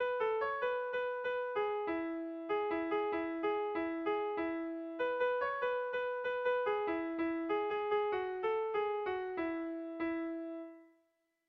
Kopla handia
ABD